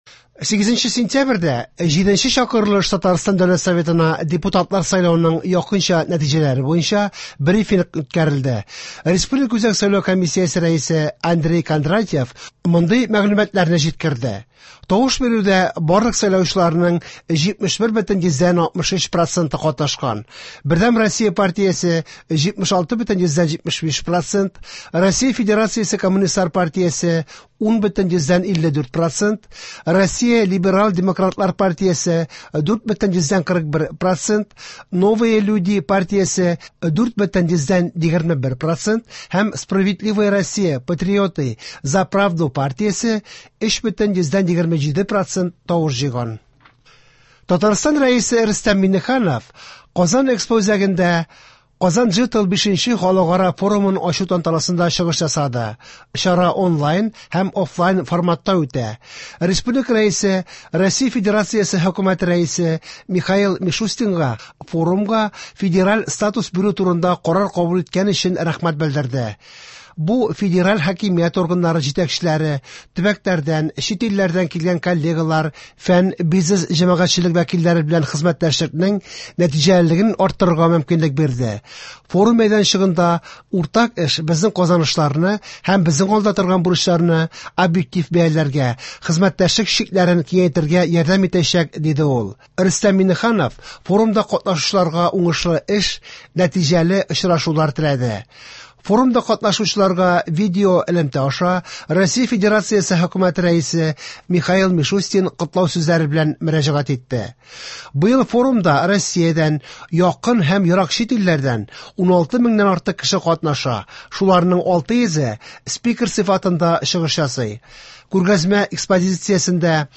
Яңалыклар (10.09.23)